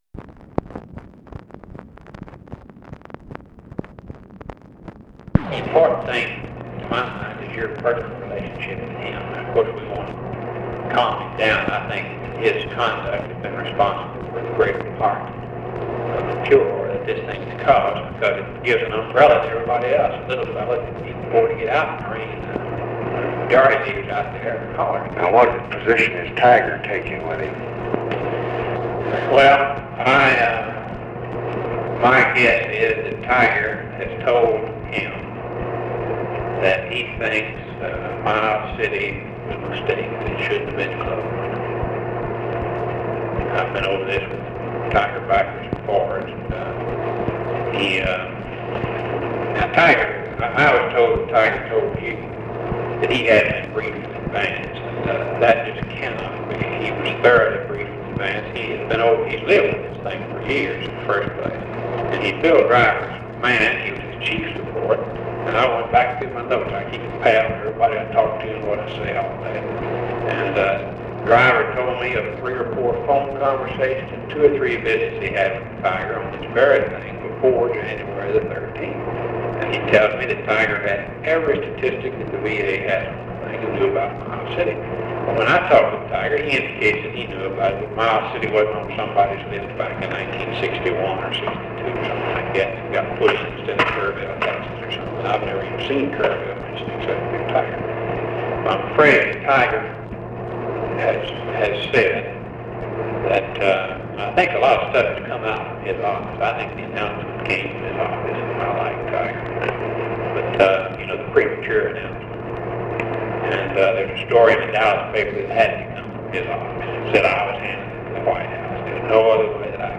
OFFICE CONVERSATION, January 28, 1965
Secret White House Tapes